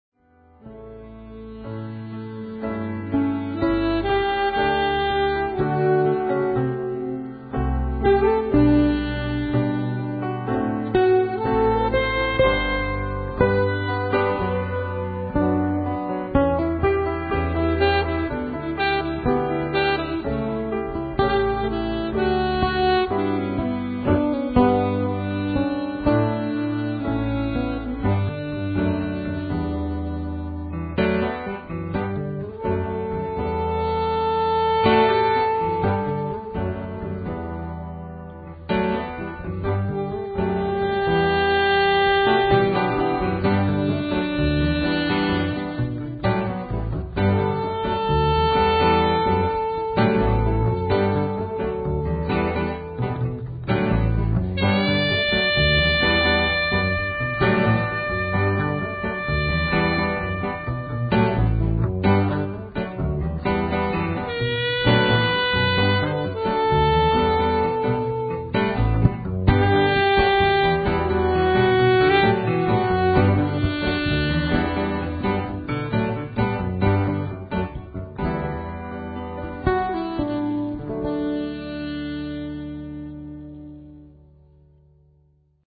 Soprano and alt saxophone
Grand piano
Double bass